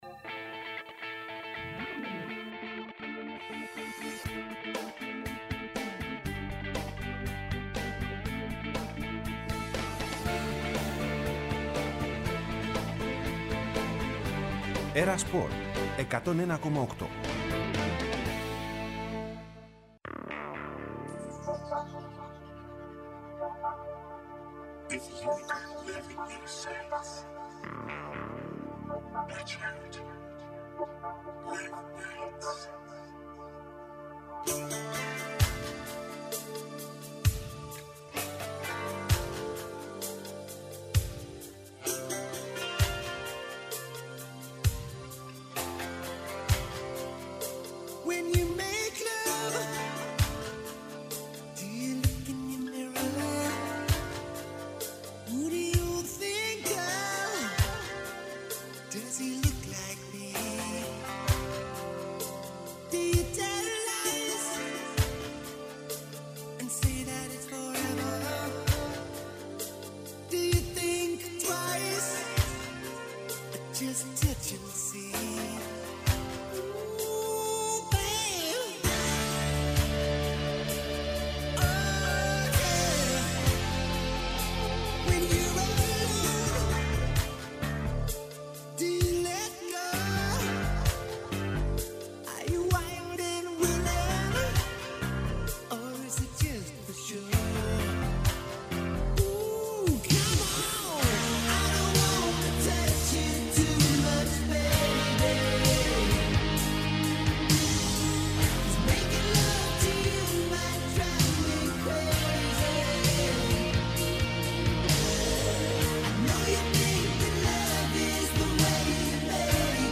Ρεπορτάζ και συνεντεύξεις, με βάση το ομότιτλο αθλητικό site της ΕΡΤ.